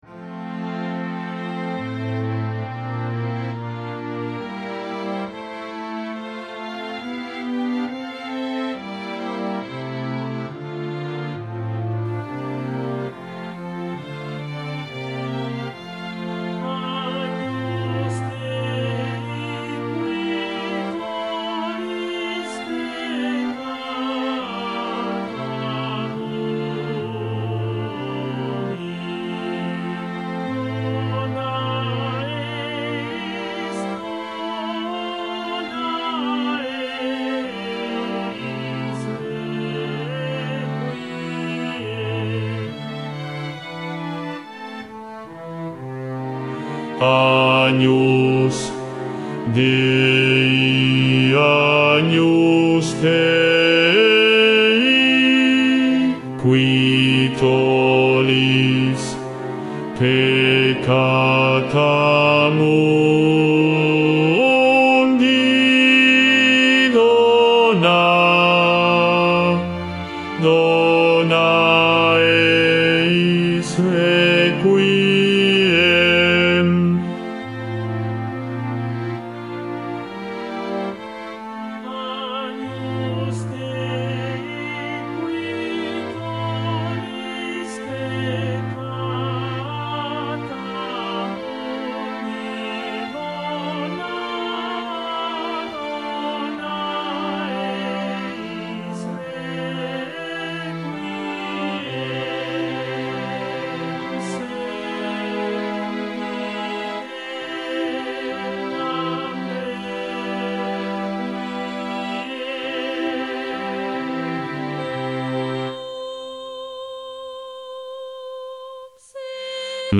Bajo II
Mp3 Profesor
5.-Agnus-Dei-BAJO-II-VOZ.mp3